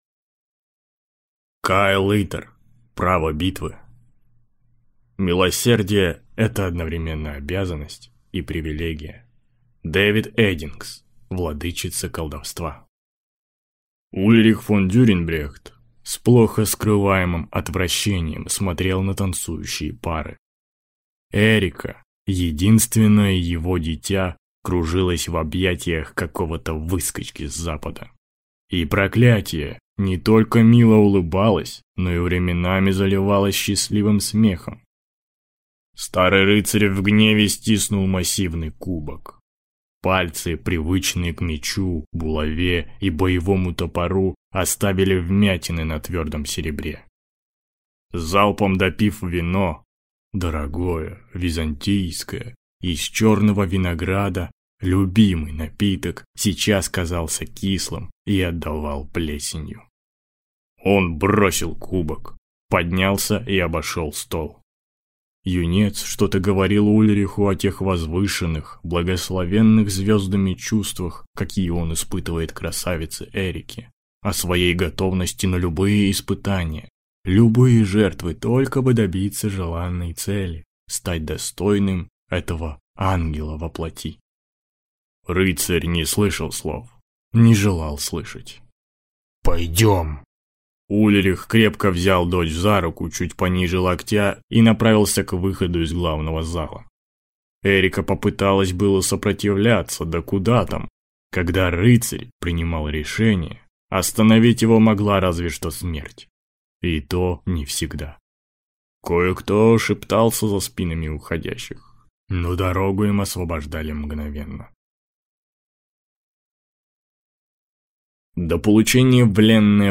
Аудиокнига Право битвы | Библиотека аудиокниг